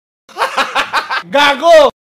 hahaha gago Meme Sound sound effects free download